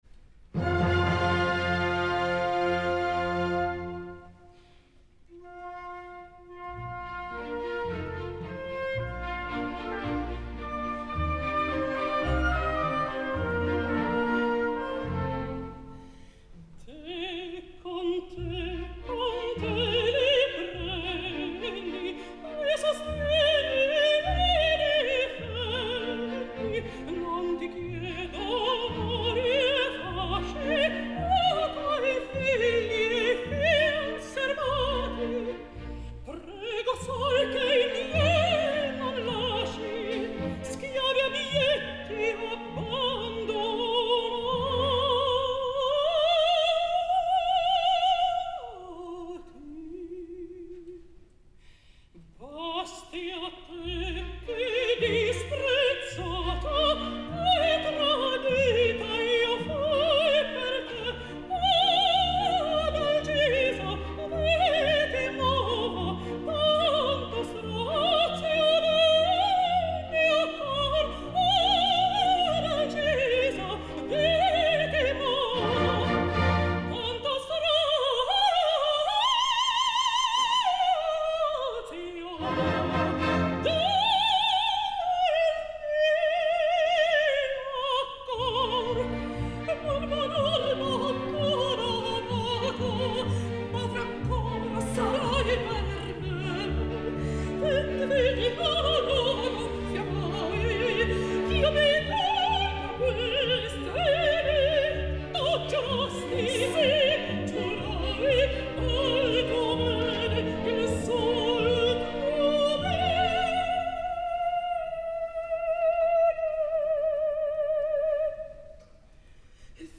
duo-2on-acte.mp3